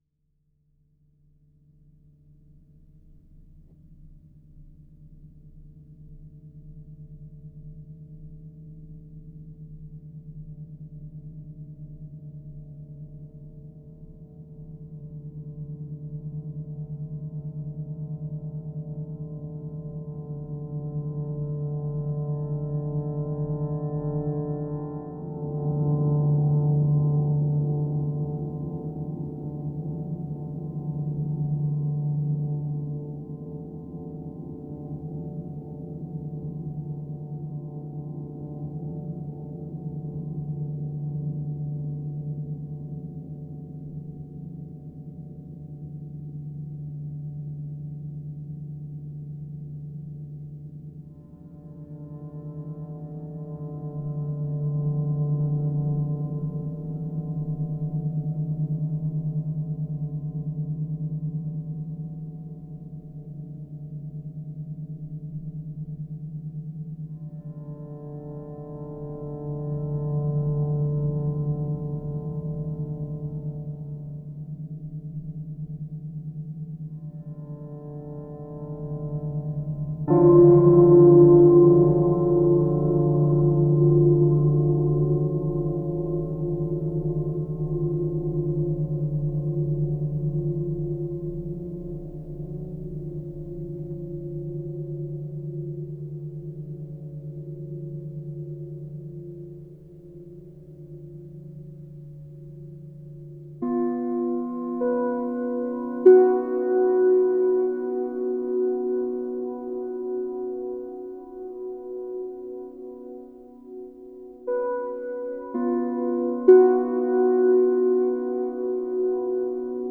In Sympathy Music for CymaPhone A portfolio of sympathetic resonance compositions enlightened by the mystical forms of rāga and sound healing.